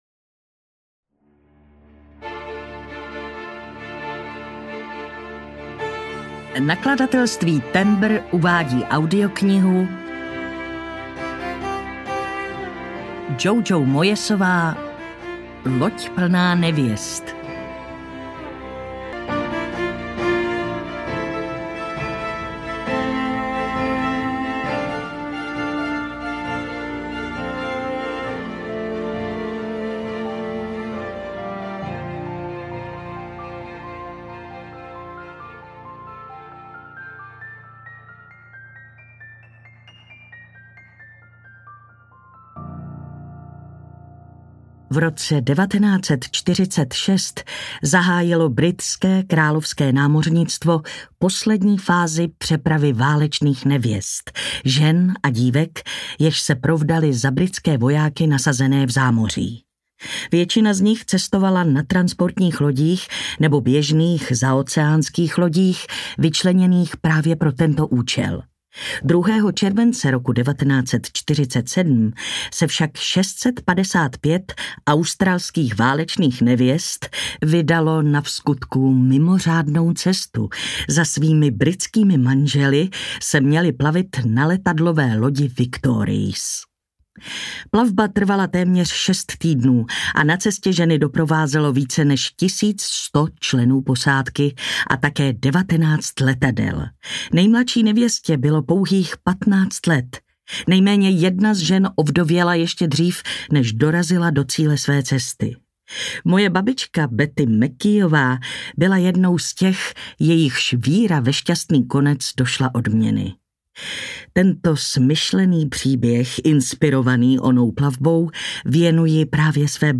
Loď plná nevěst audiokniha
Ukázka z knihy
• InterpretMartina Hudečková